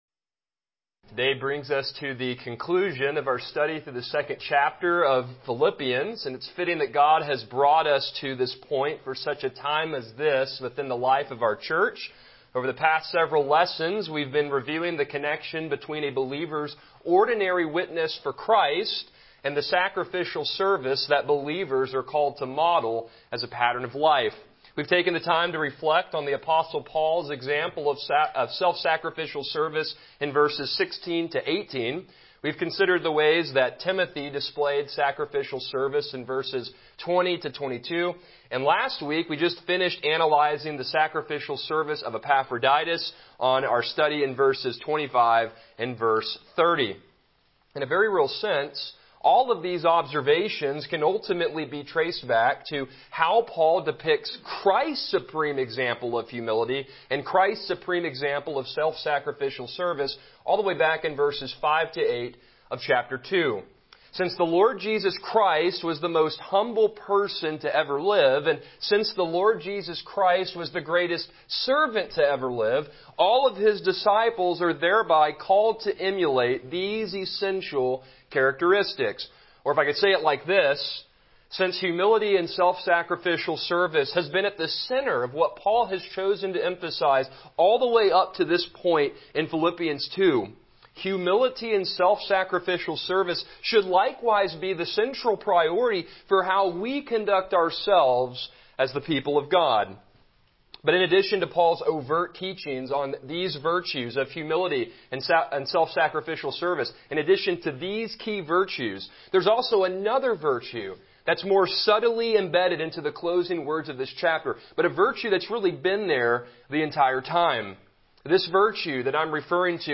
Passage: Philippians 2:19, 23-24, 26-29 Service Type: Morning Worship